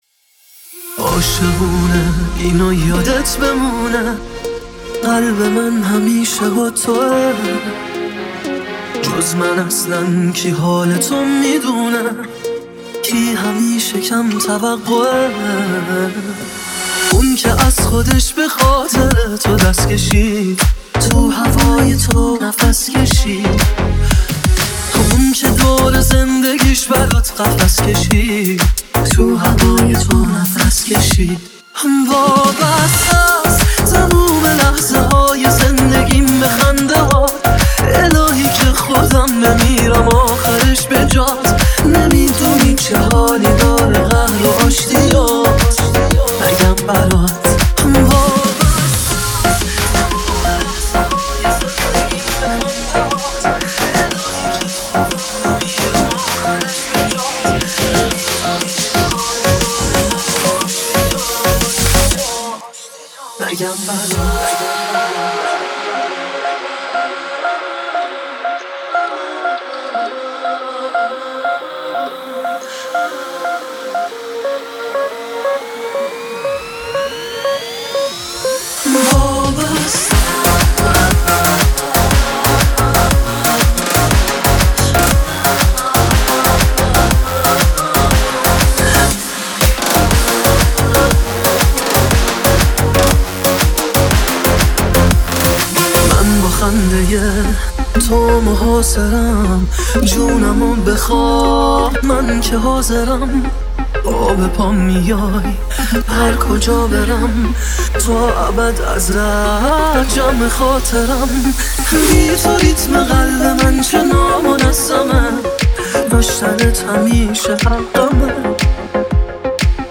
بیس دار